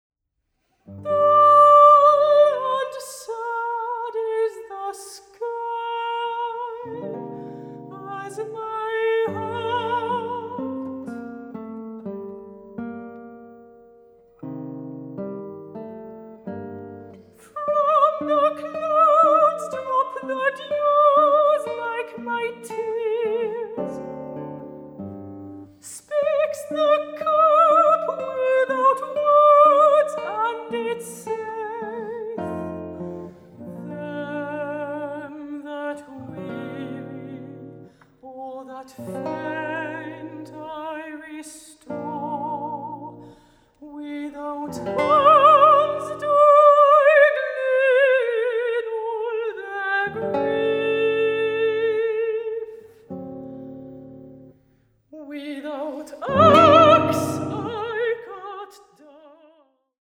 Gitarre
Gesang